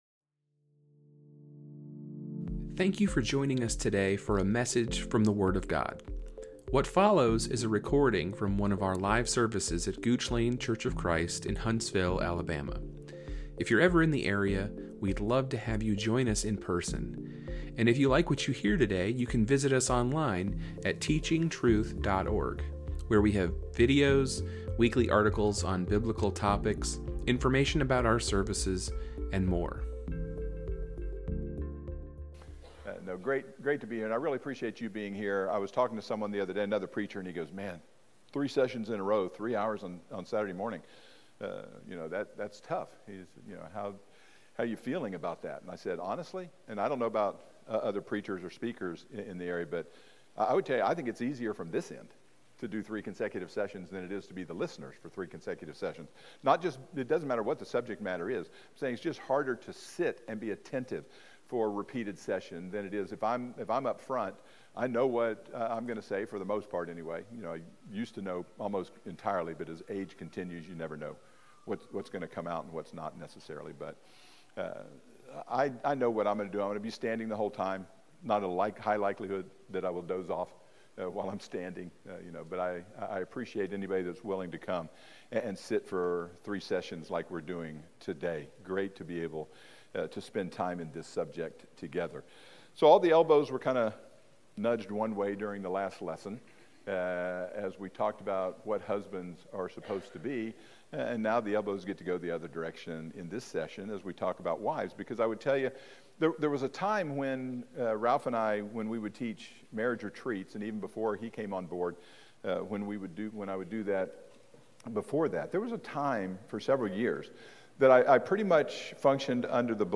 Lesson three of a seven part marriage and family series titled ‘Unless the Lord Builds The House’ by guest preacher